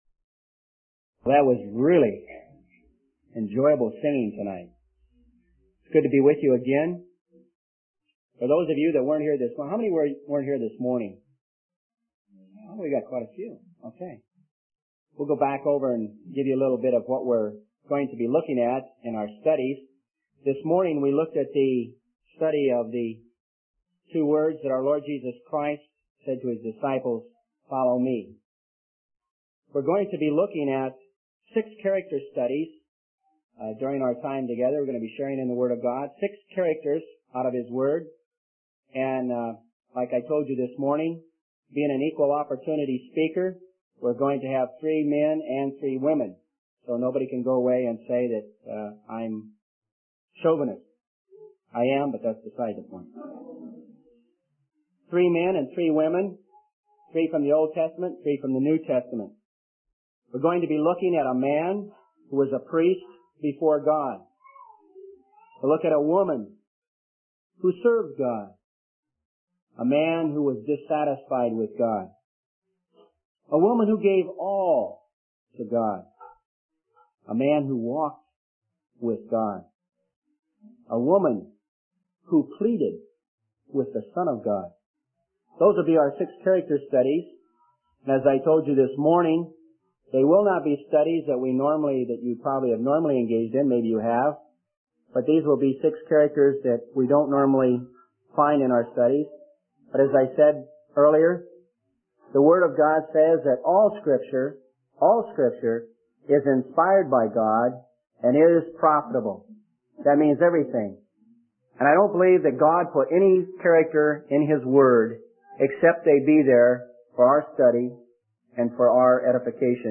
In this sermon, the speaker begins by acknowledging the absence of some attendees from the morning session and provides a brief recap of the previous study on the words of Jesus, 'follow me.' The speaker then introduces the topic of six character studies from the Bible, three men and three women, to explore during the sermon series. The sermon emphasizes the importance of contentment and obedience in the face of trials and challenges, using the example of Gehazi's discontentment leading to negative consequences.